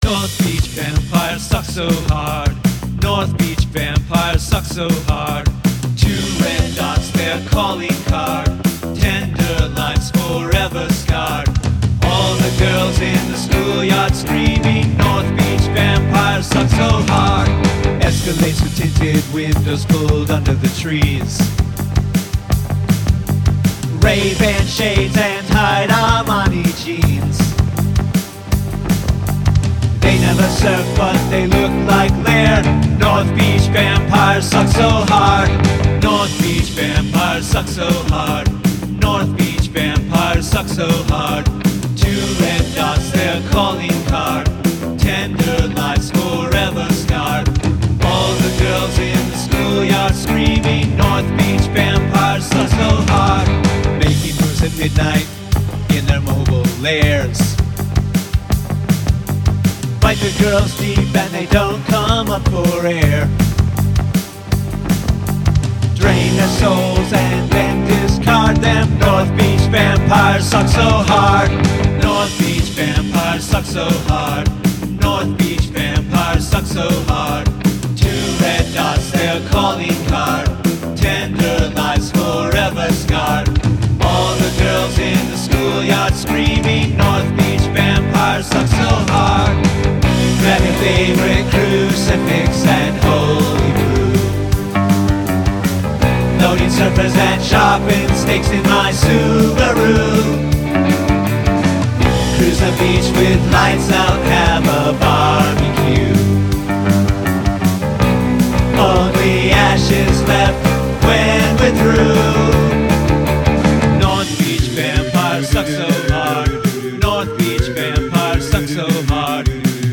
Include an a cappella section